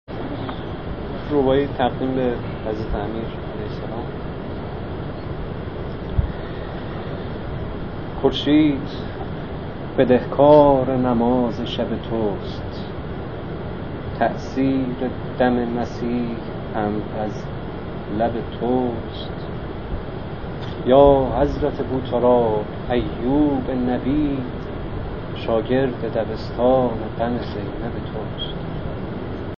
جلسه شعر آیینی